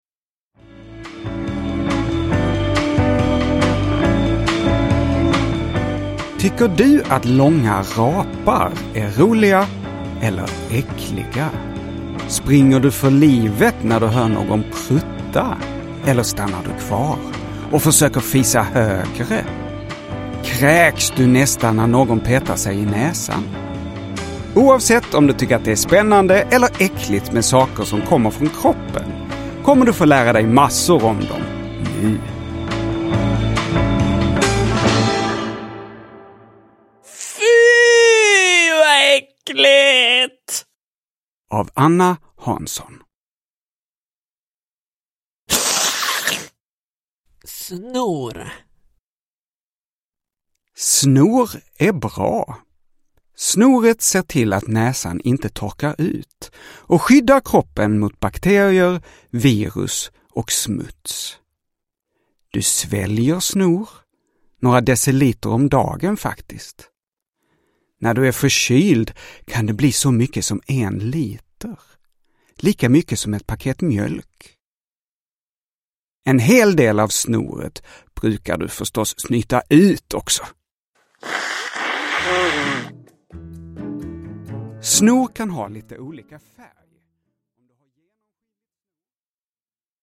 Fy, vad äckligt! – Ljudbok – Laddas ner